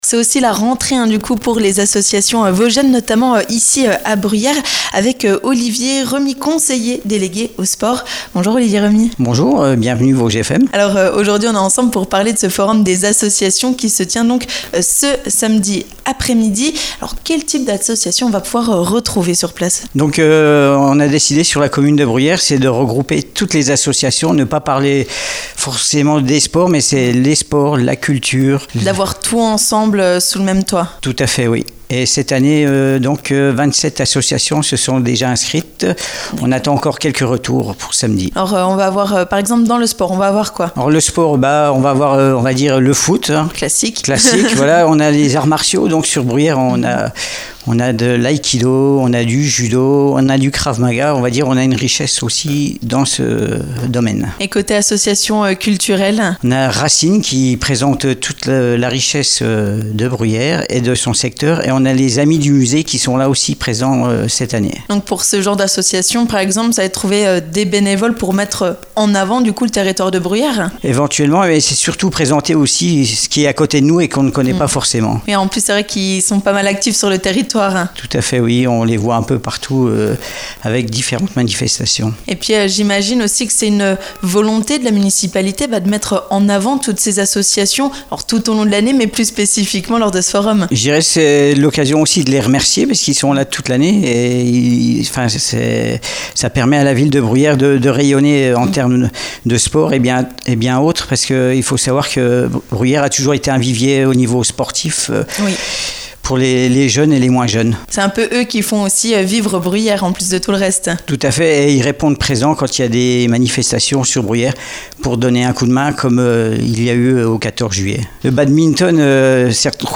Olivier Remy, conseiller délégué aux sports de la Ville de Bruyères nous explique comment le basket va se refaire une place dans la commune !